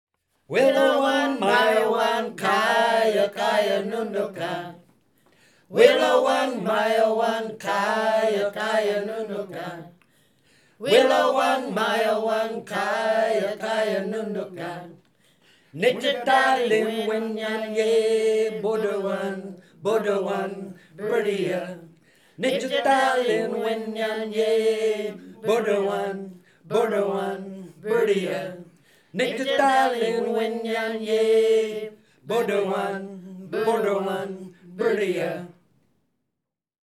This song was developed in Wirlomin workshops and inspired by an older Wirlo song.